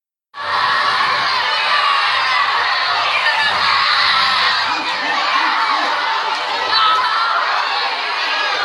Crowd of Boys Cheering
boys cheer cheering dominance effect excited happy hoot sound effect free sound royalty free Sound Effects